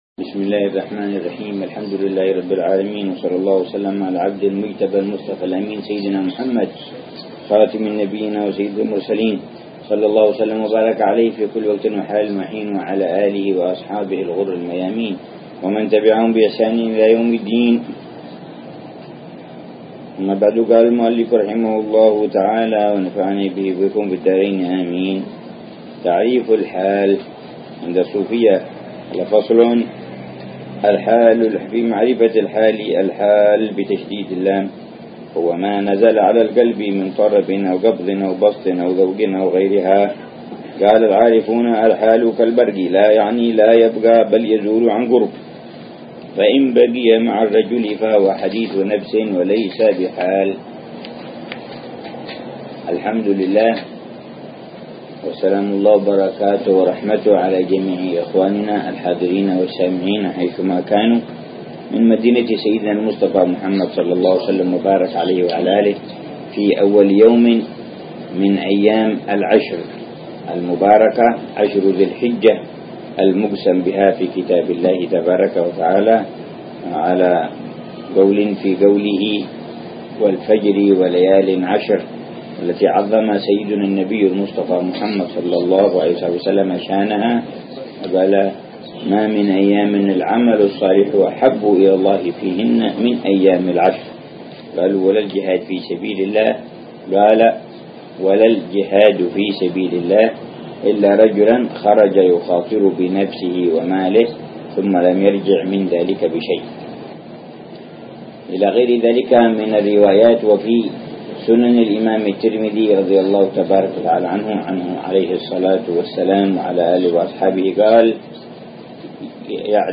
درس أسبوعي يلقيه الحبيب عمر بن حفيظ في كتاب الكبريت الأحمر للإمام عبد الله بن أبي بكر العيدروس يتحدث عن مسائل مهمة في تزكية النفس وإصلاح القلب